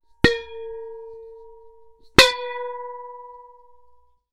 Metal_58.wav